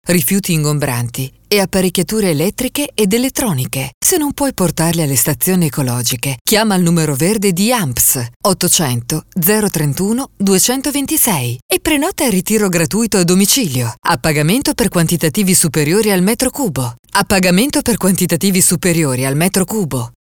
Spot per raccolta rifiuti ingombranti